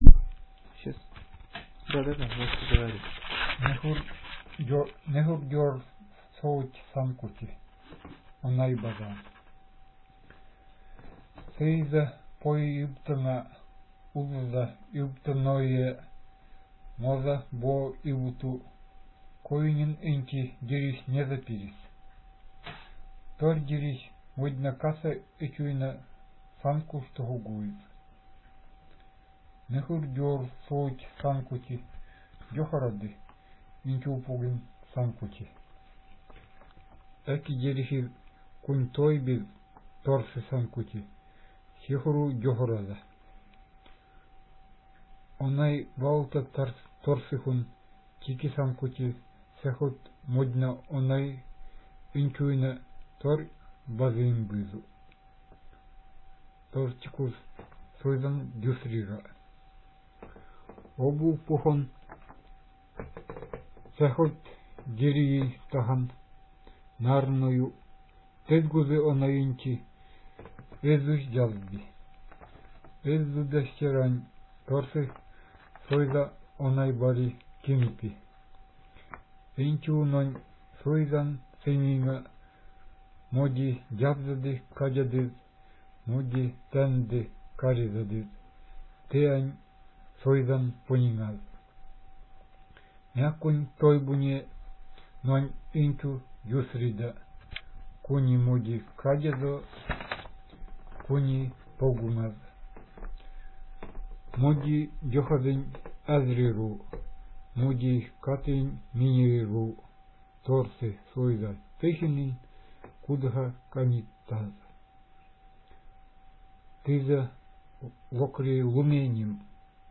Dialect Forest with Tundra influence
Settlement Potapovo